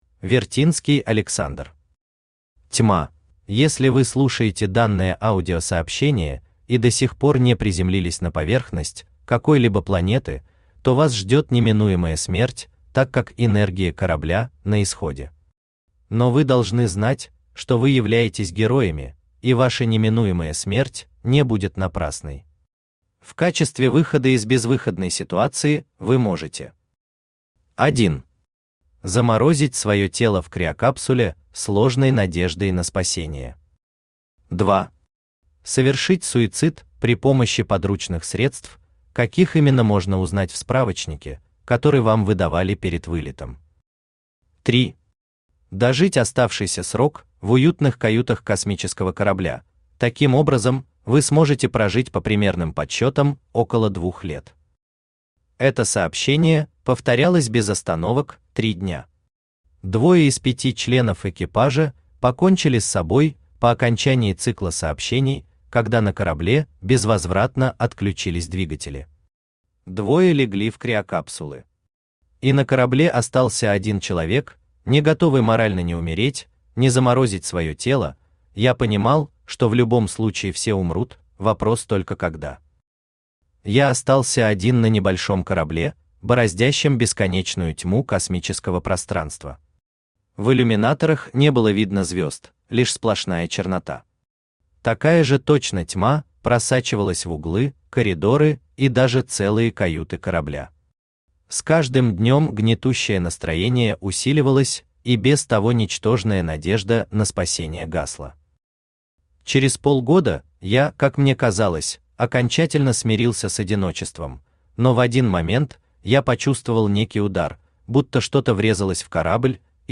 Аудиокнига Тьма | Библиотека аудиокниг
Aудиокнига Тьма Автор Вертинский Александр Читает аудиокнигу Авточтец ЛитРес.